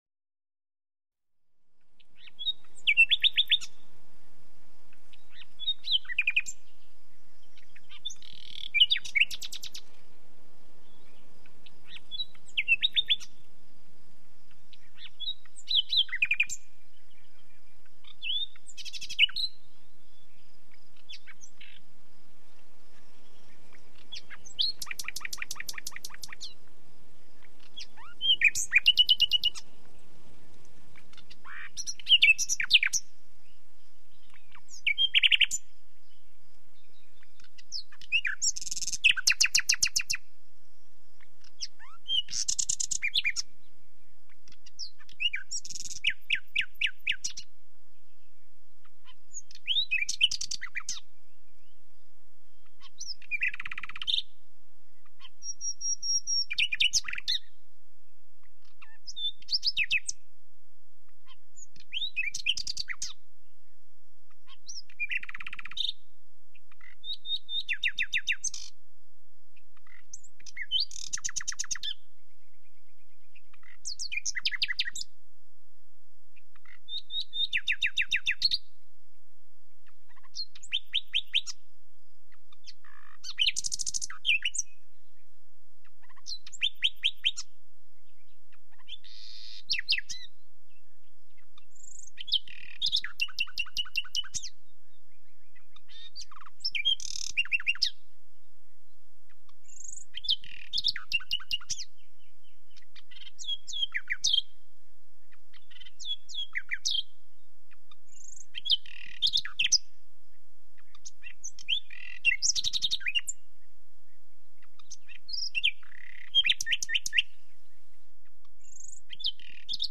Звуки пения птиц
Западный лесной соловей